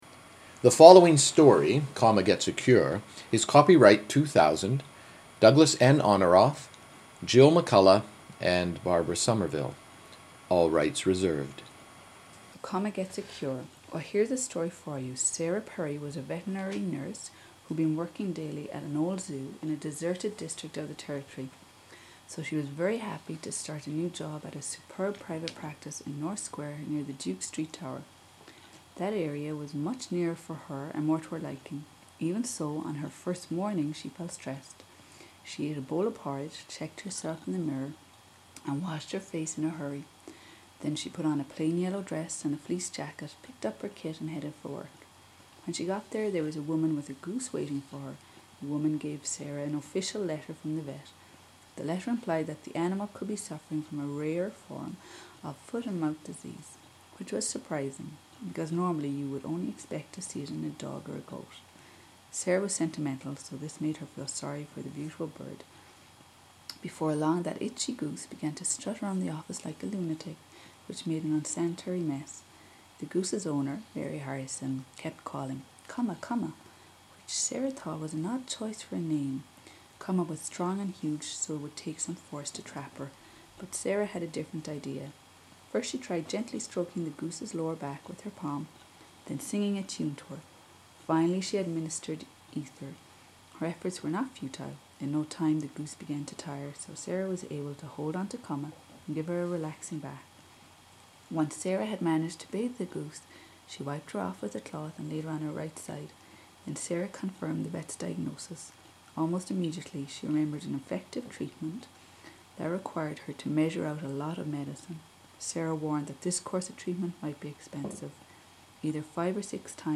Cork City
Female.
Ireland :: Common Text
irish_common.mp3